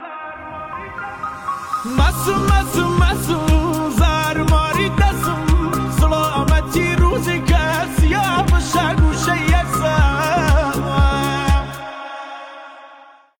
ترانه کردی